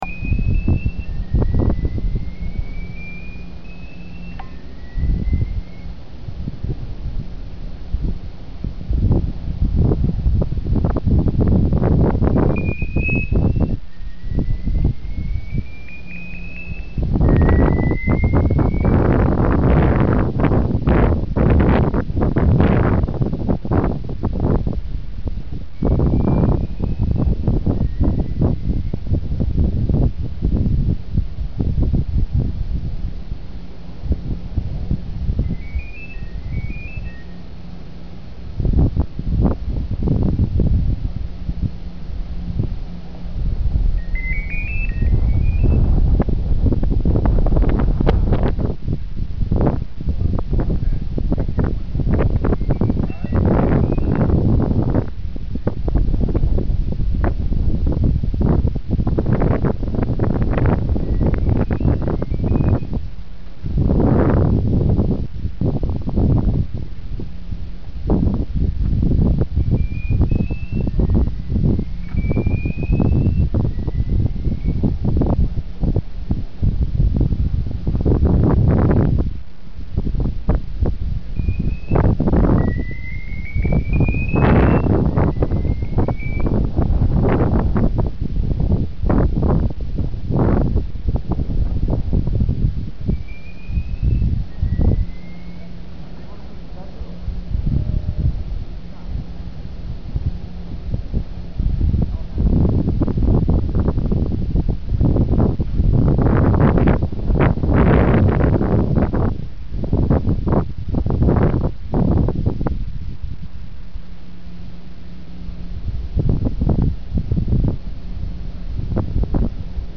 If so, strange noises may have been heard like the song of an extraterrestrial bird.
During operation, the system emits a multi-tone acoustic pulse directly into the atmosphere at 10-second intervals and then registers the return signal.
sodarSoundAtVisitorParking.mp3